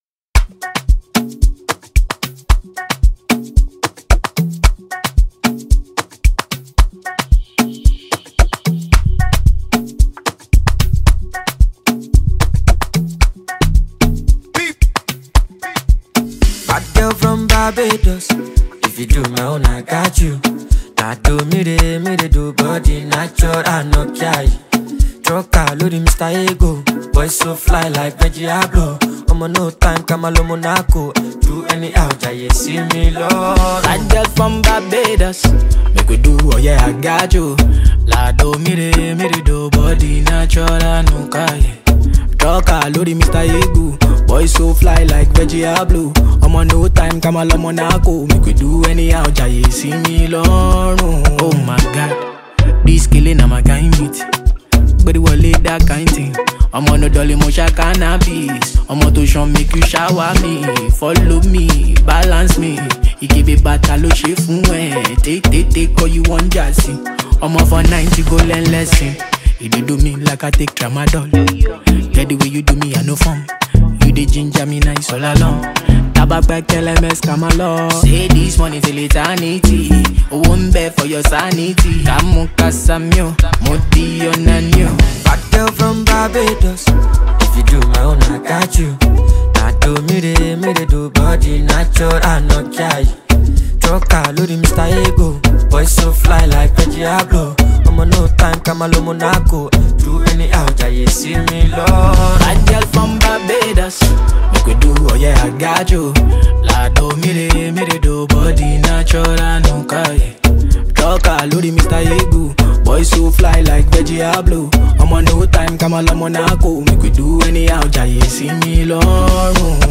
Grammy Award winning Nigerian heavyweight Afrobeat Singer